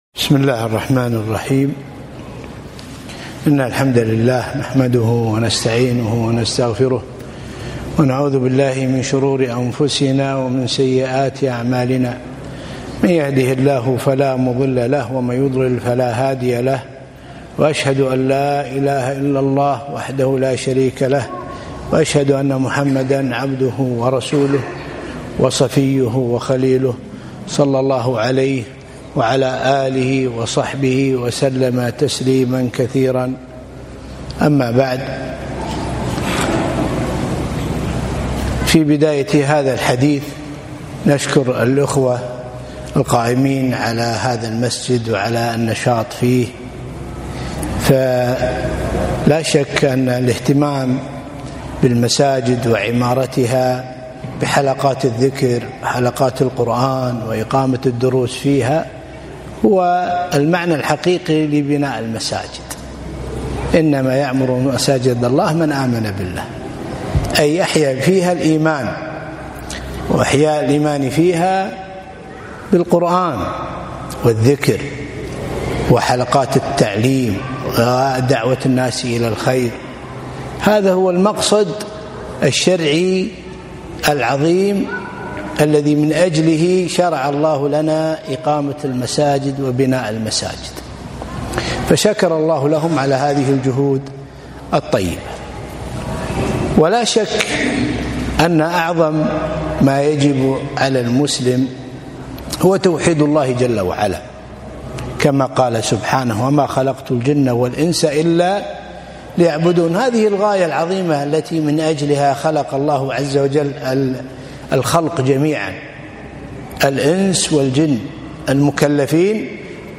محاضرة - حقيقة تعظيم العلم وتوقيره